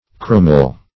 caromel - definition of caromel - synonyms, pronunciation, spelling from Free Dictionary Search Result for " caromel" : The Collaborative International Dictionary of English v.0.48: Caromel \Car"o*mel\, n. See Caramel .
caromel.mp3